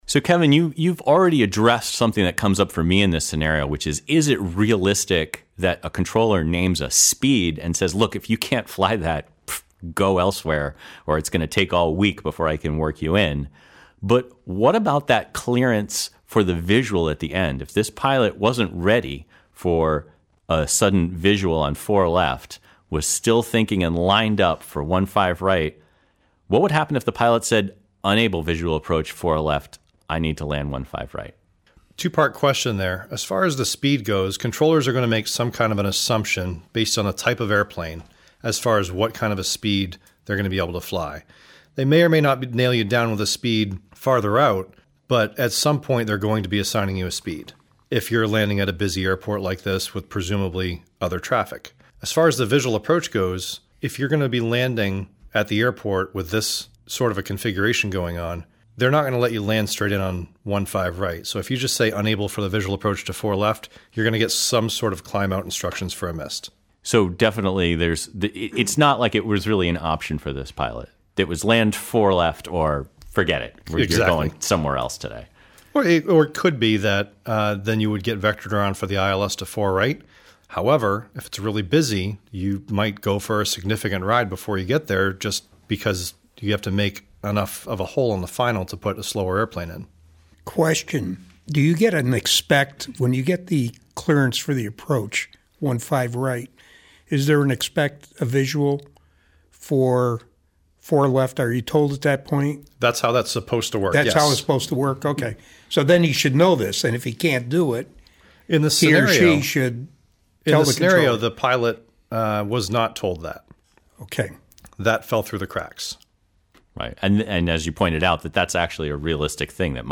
Boston_Bait_and_switch_roundtable.mp3